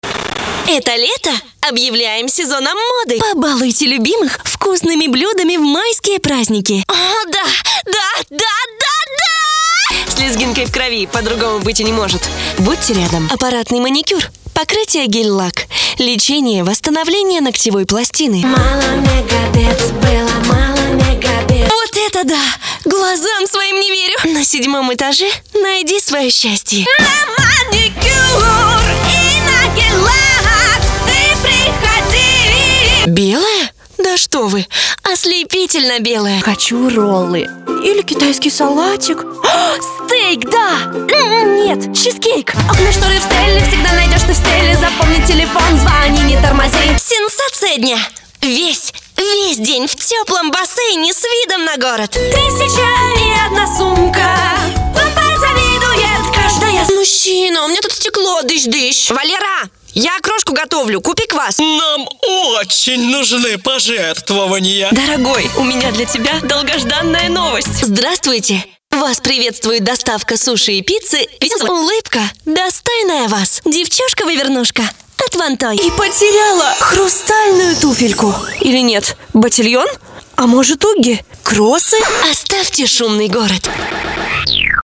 Дикторские голоса (Озвучка)
Женские